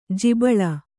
♪ jibaḷa